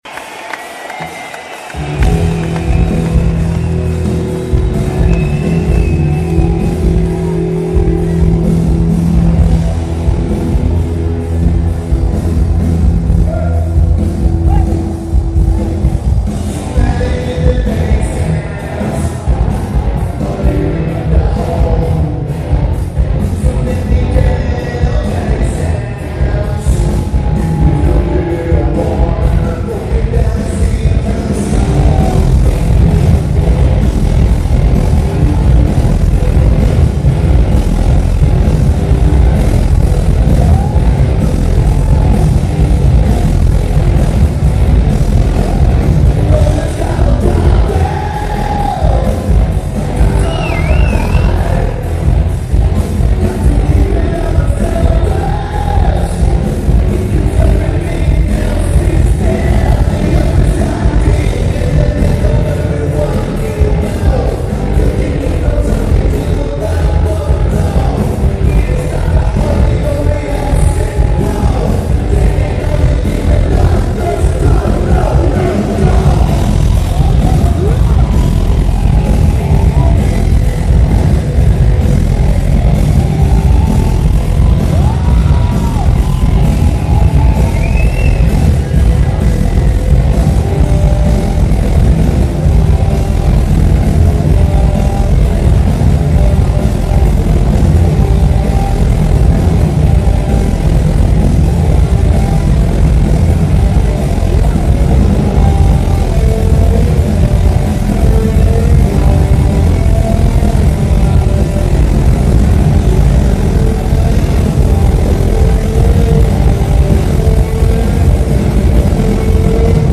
Slavia Zimni Sdion
Prague Czech Republic